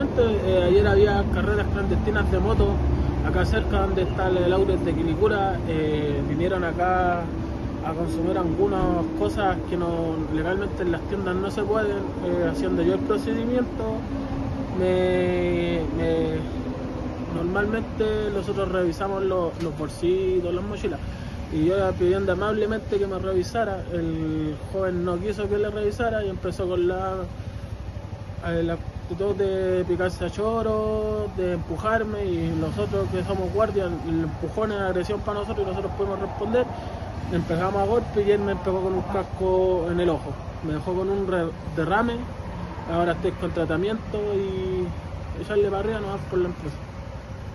Radio Bío Bío se trasladó al lugar y pudo constatar que esta gasolinera es un foco frecuente de hechos delictivos. Trabajadores aseguraron que han sufrido hasta cuatro robos por semana, siempre por la misma banda delictual.